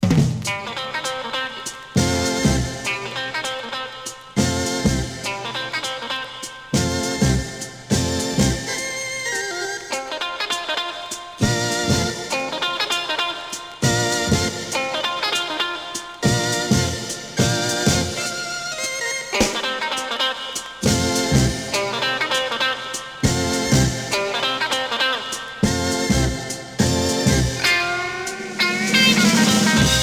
Rock instrumental Troisième EP retour à l'accueil